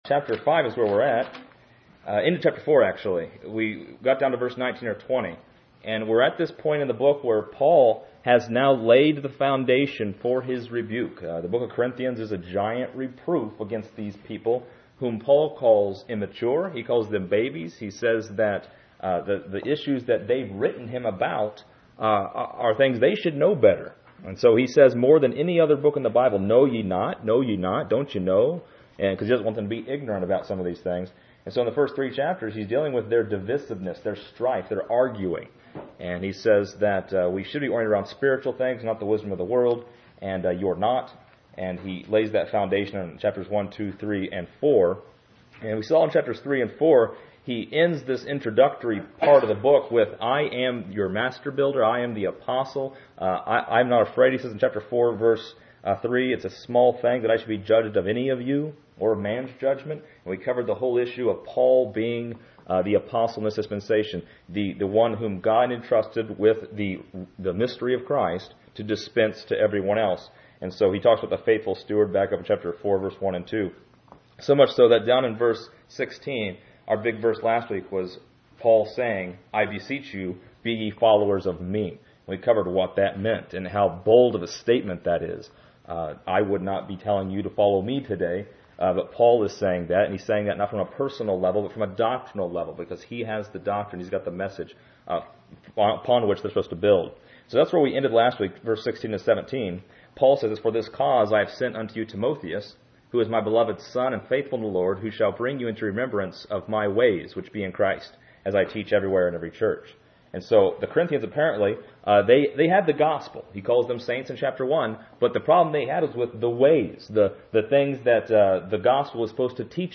This lesson is part 13 in a verse by verse study through 1 Corinthians titled: A Powerful Judgment.